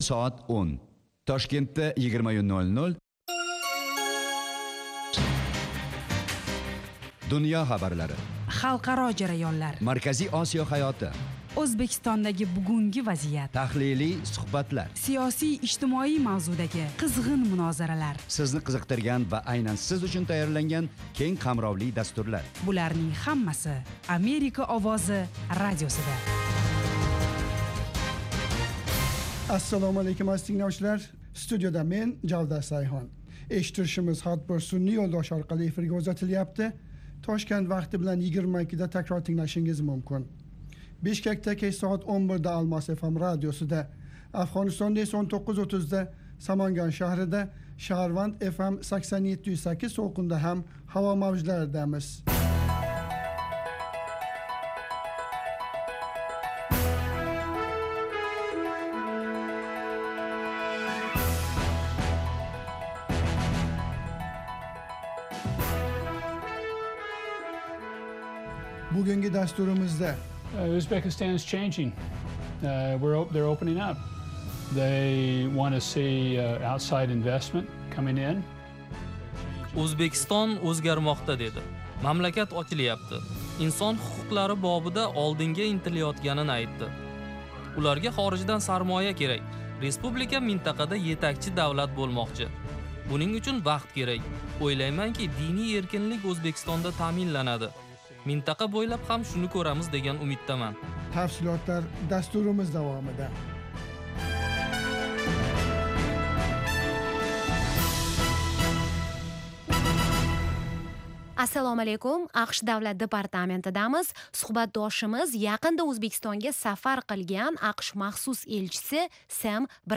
Toshkent vaqti bilan har kuni 20:00 da efirga uzatiladigan 30 daqiqali radio dastur kunning dolzarb mavzularini yoritadi. Xalqaro hayot, O'zbekiston va butun Markaziy Osiyodagi muhim o'zgarishlarni, shuningdek, AQSh bilan aloqalarni tahlil qiladi. Eshittirishlarda bu davlatda yashayotgan o'zbeklar hayoti bilan tanishasiz.